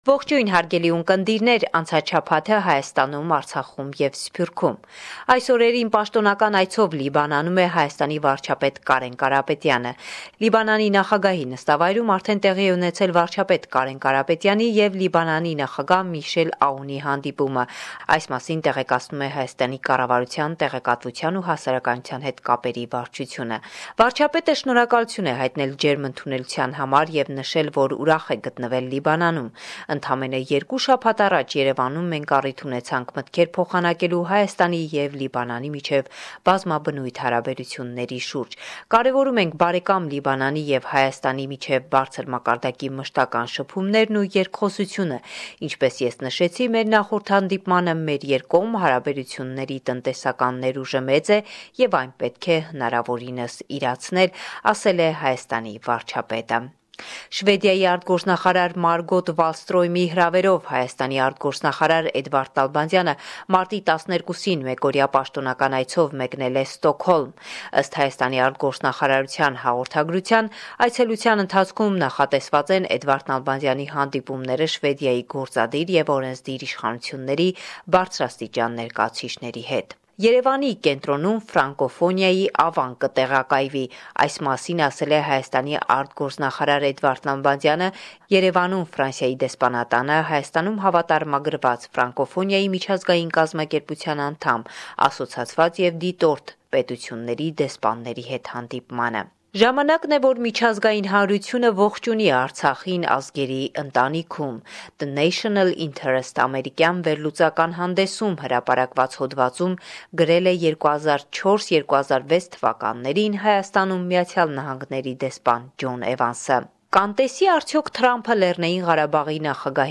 Վերջին Լուրերը – 13 Մարտ 2018